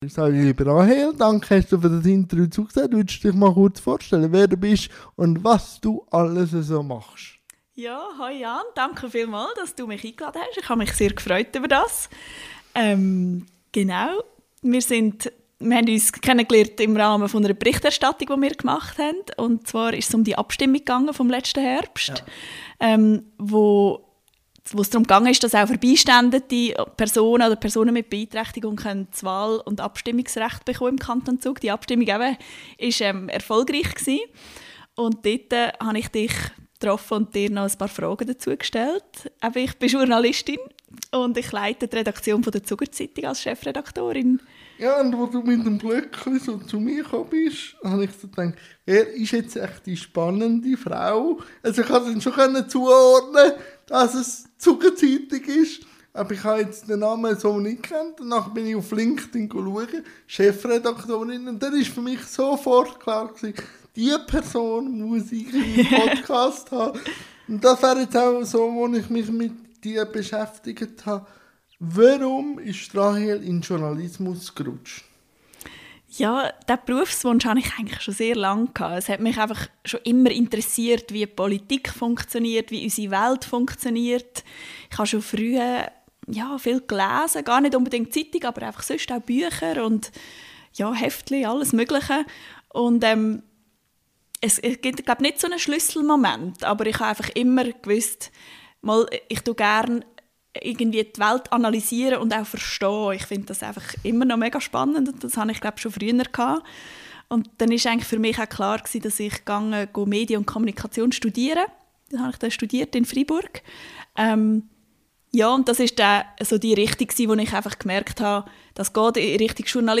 INTERVIEW-THEMEN